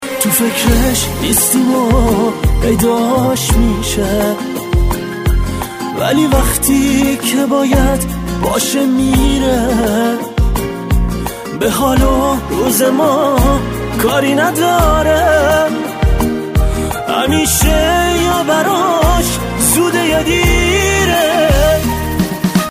زنگ موبایل با کلام